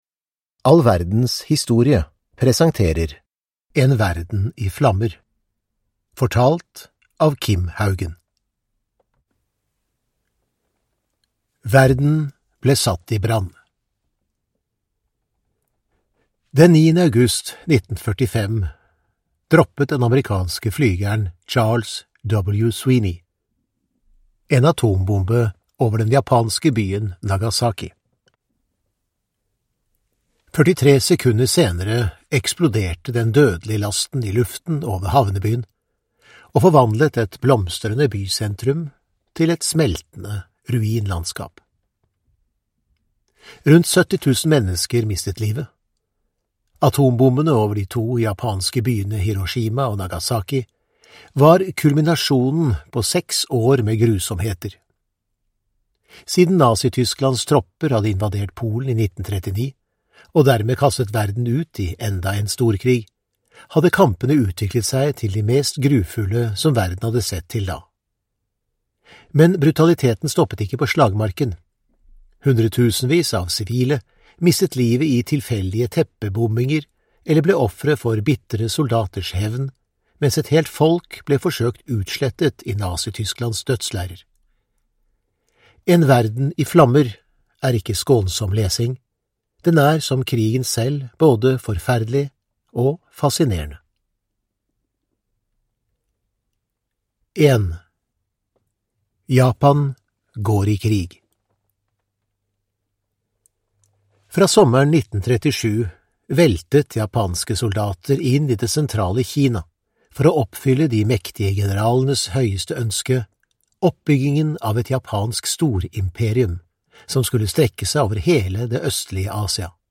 En verden i flammer (ljudbok) av All verdens historie